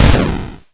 ihob/Assets/Extensions/explosionsoundslite/sounds/bakuhatu151.wav at master
bakuhatu151.wav